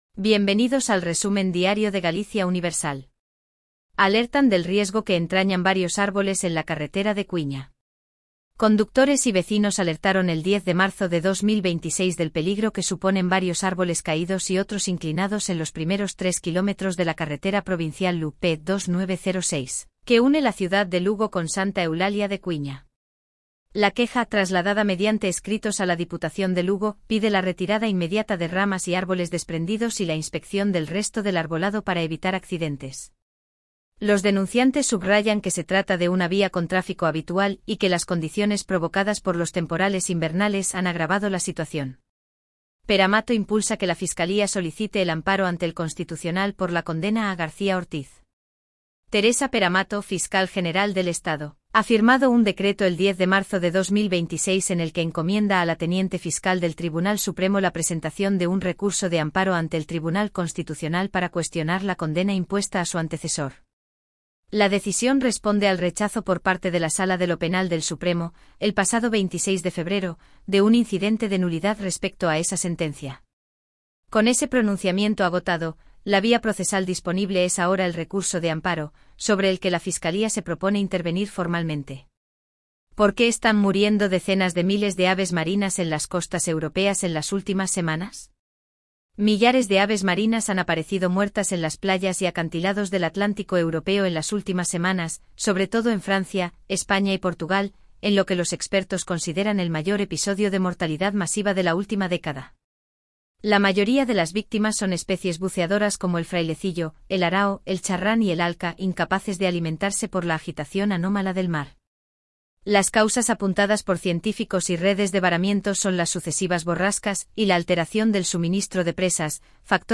Voz: Elvira · Generado automáticamente · 5 noticias